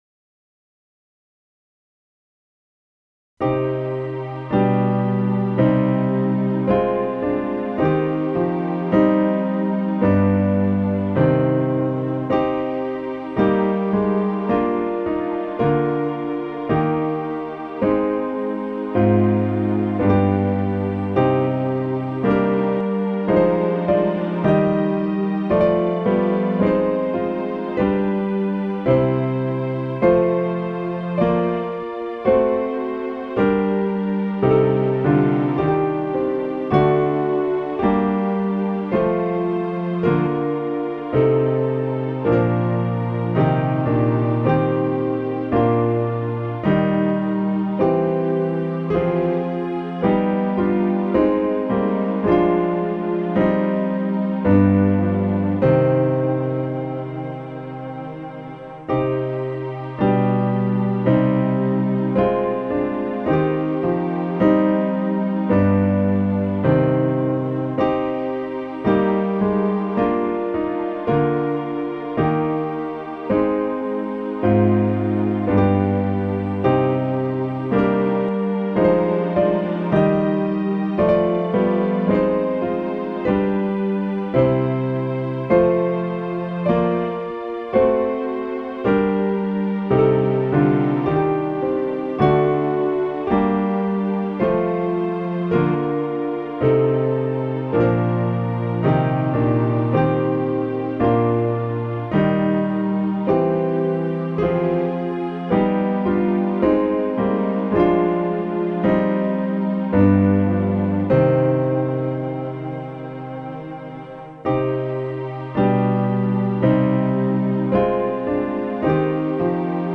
Click the Button to sing the prayer (key for Men), or play the song in a New Window